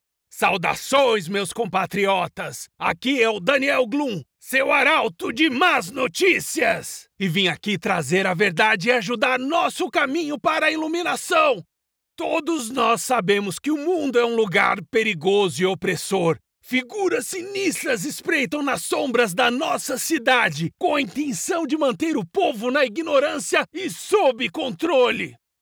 Ma configuration de studio à la pointe de la technologie garantit une qualité sonore exceptionnelle pour chaque projet.
Cabine acoustique parfaite